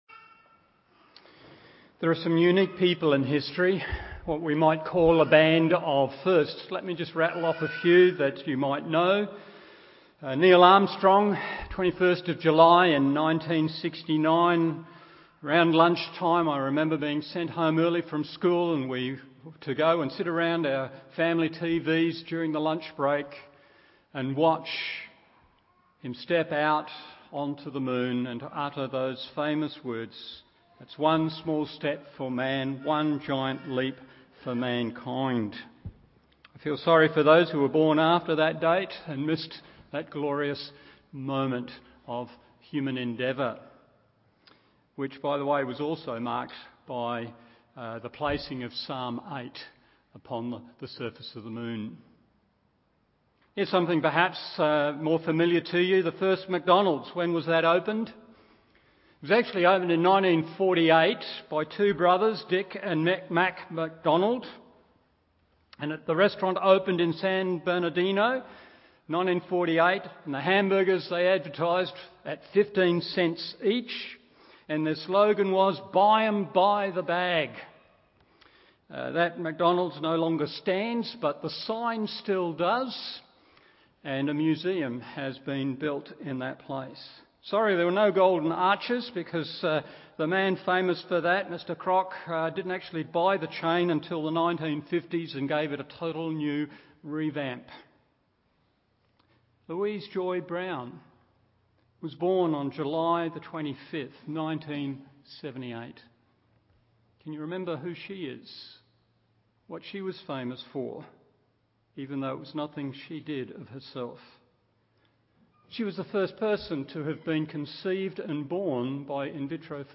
Morning Service Acts 2:23-24 1. Not Limited in purpose 2. Not Indifferent to Sin 3. Not Limited in power…